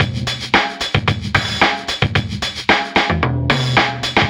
Track 15 - Drum Break 05.wav